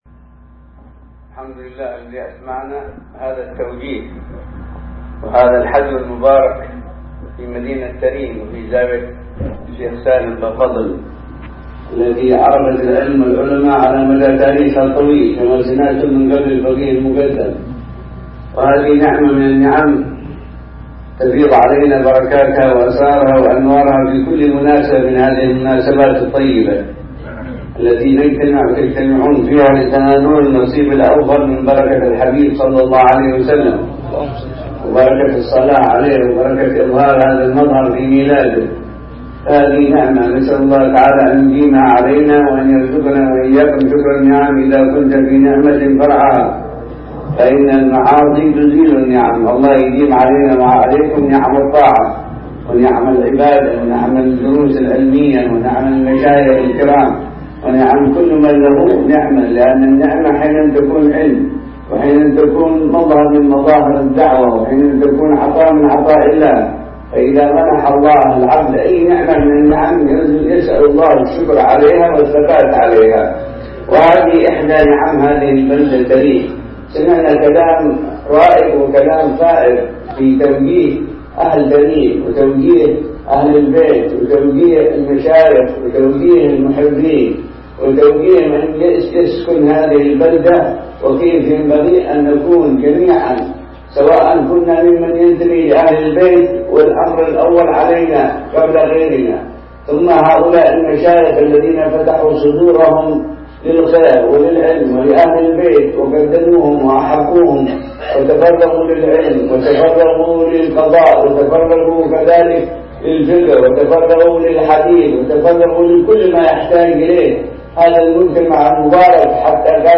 كلمة
بزاوية الشيخ سالم بافضل – تريم – حضرموت